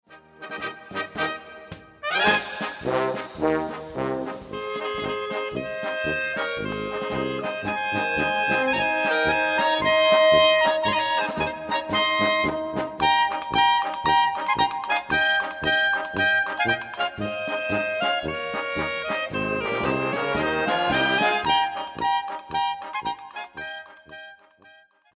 polka